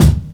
Boom-Bap Kick 74.wav